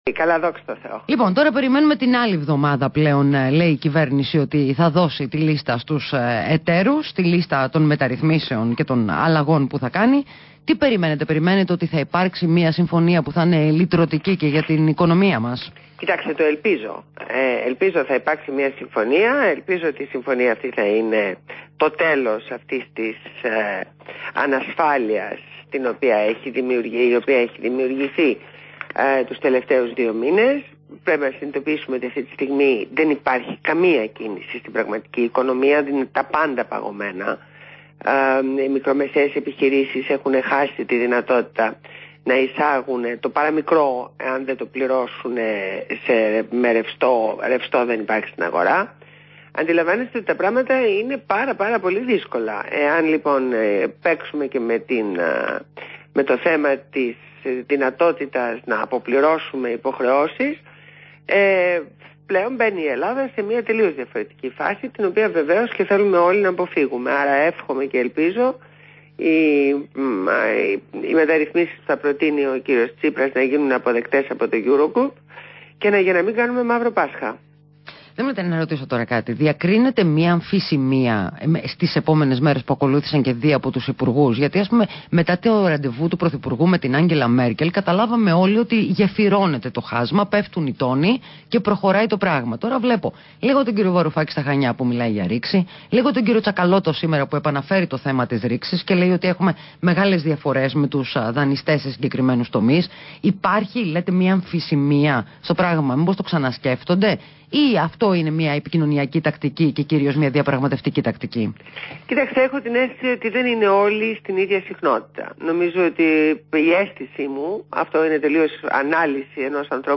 Ακούστε τη ραδιοφωνική συνέντευξη στο REALfm